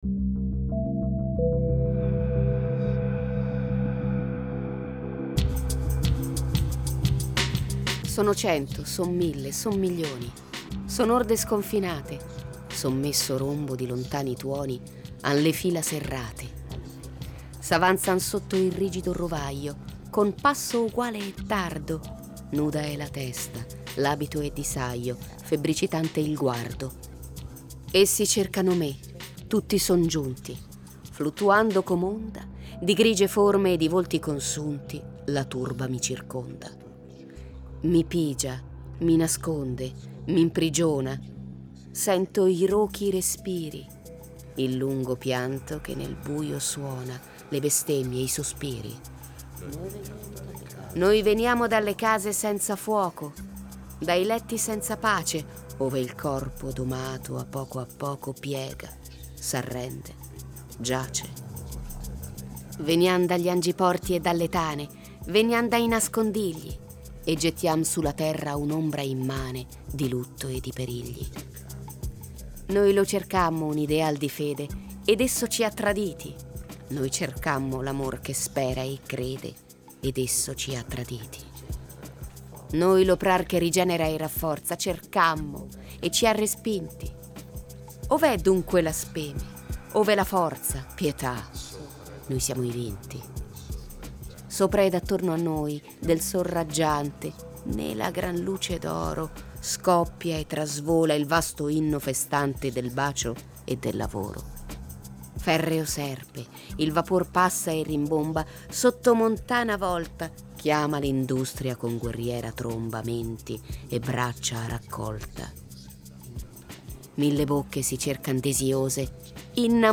Letto da